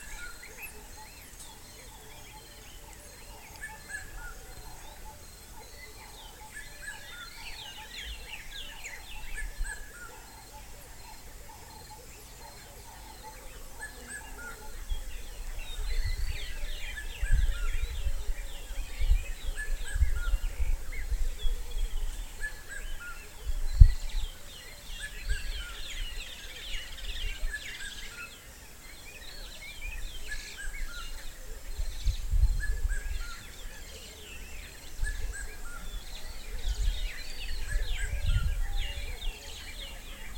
Red-chested Cuckoo (Cuculus solitarius)
Country: Uganda
Location or protected area: Parque Nacional Lago Mburo
Condition: Wild
Certainty: Recorded vocal
Cuculus-solitarius-Y-MUCHOS-OTROS.mp3